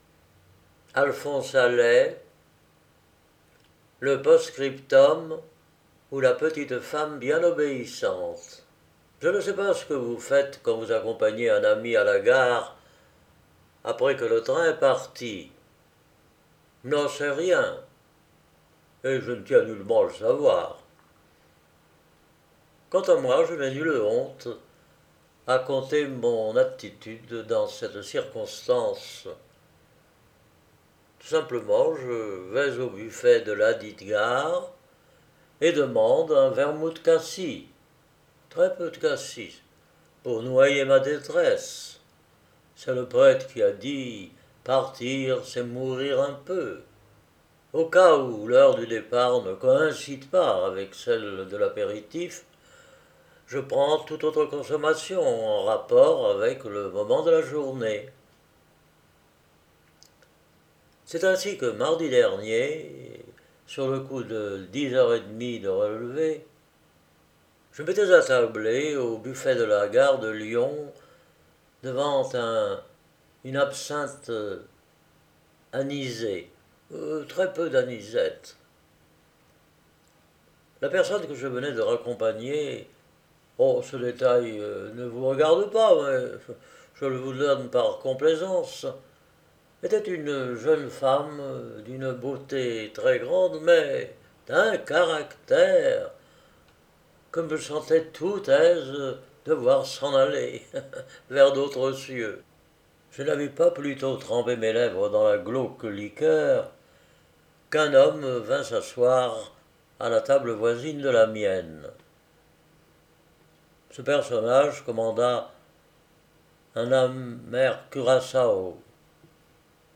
Genre : Nouvelles